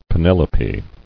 [Pe·nel·o·pe]